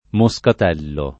moscatello [ mo S kat $ llo ]